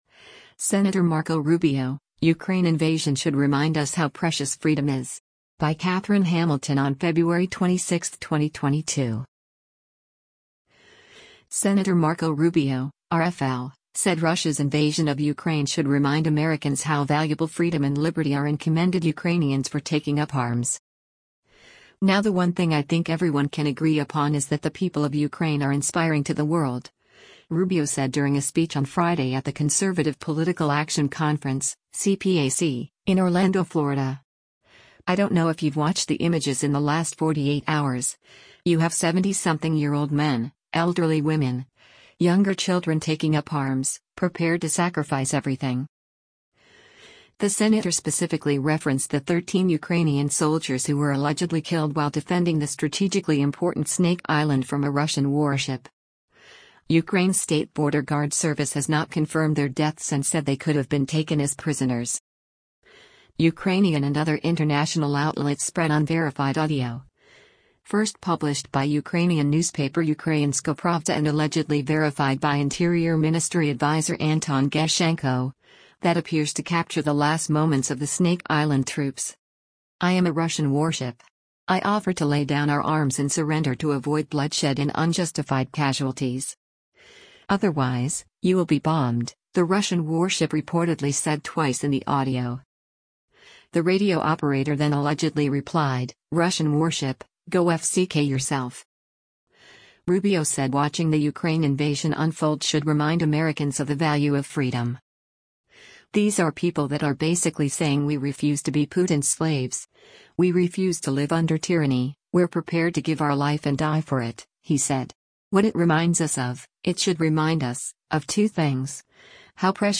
“Now the one thing I think everyone can agree upon is that the people of Ukraine are inspiring to the world,” Rubio said during a speech on Friday at the Conservative Political Action Conference (CPAC) in Orlando, Florida.